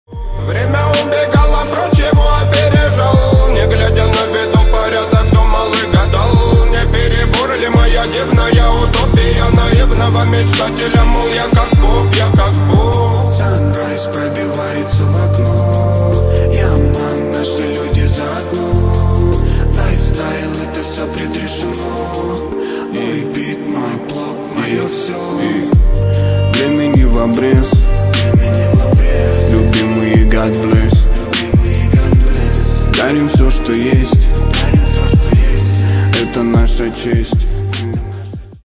позитивные
Хип-хоп
душевные